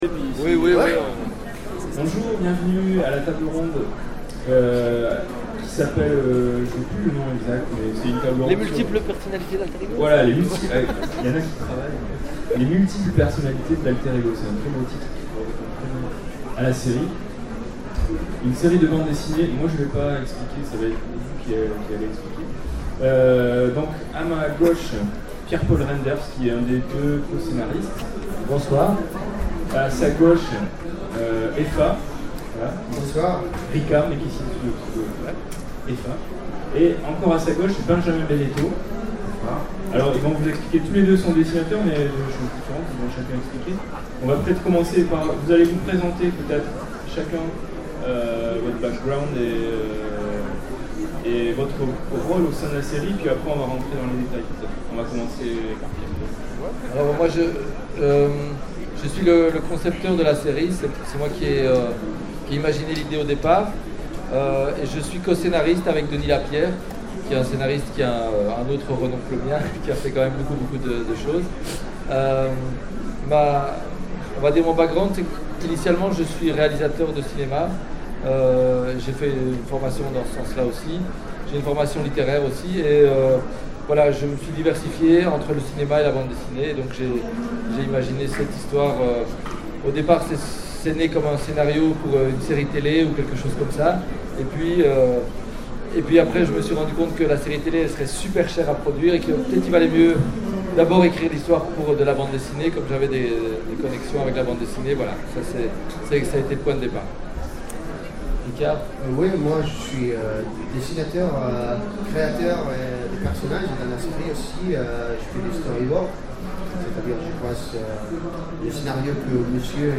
Utopiales 12 : Conférence Les multiples personnalités d’Alter Ego